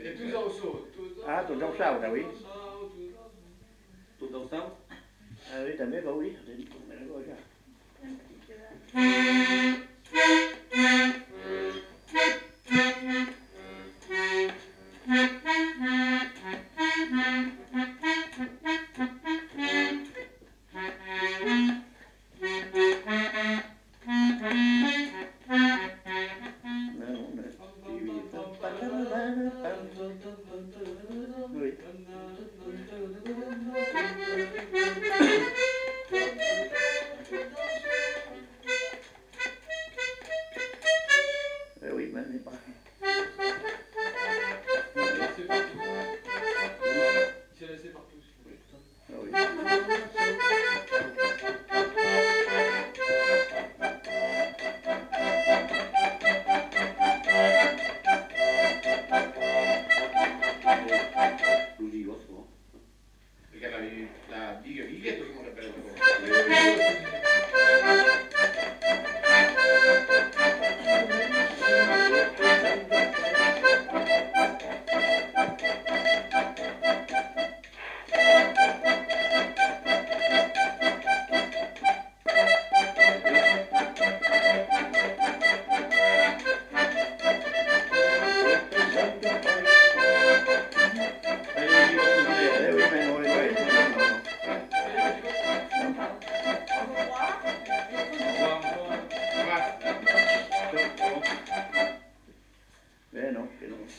Lieu : Lencouacq
Genre : morceau instrumental
Instrument de musique : accordéon diatonique
Danse : bigue-biguette